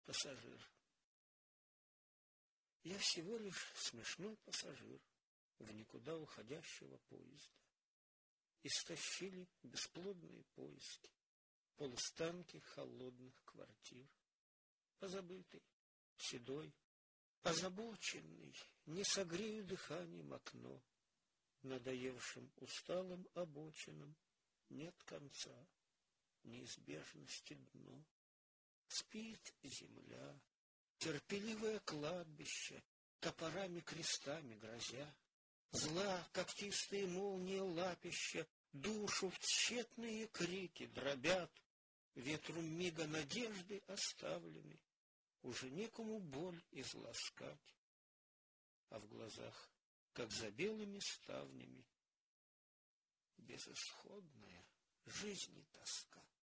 Declamation